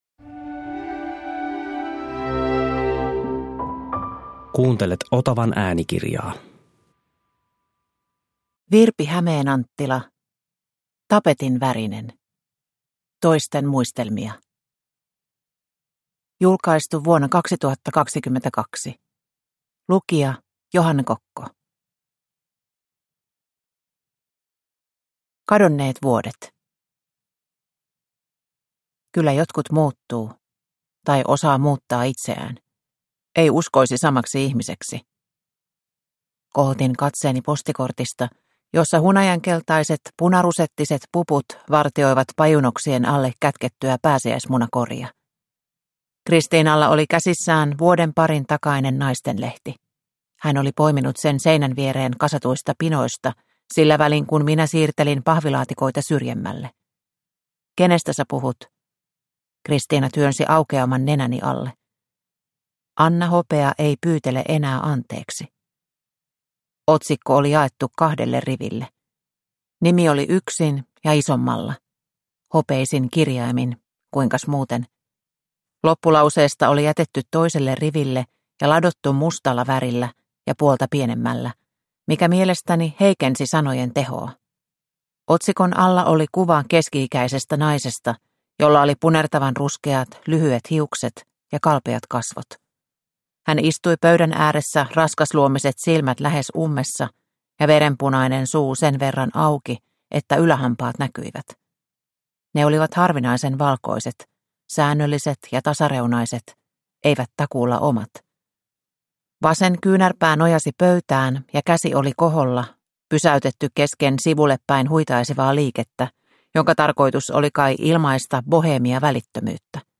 Tapetinvärinen – Ljudbok – Laddas ner